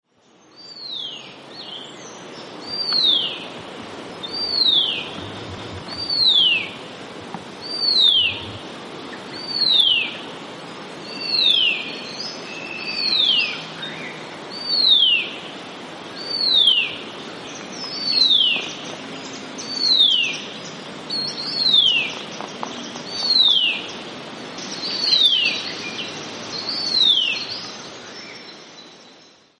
Horsfields Bronze-cuckoo - Chrysococcyx basalis
Voice: descending 'tsew' repeated persistently.
Call 1: repeated 'tsew'
Horsfields_Bronzecuck.mp3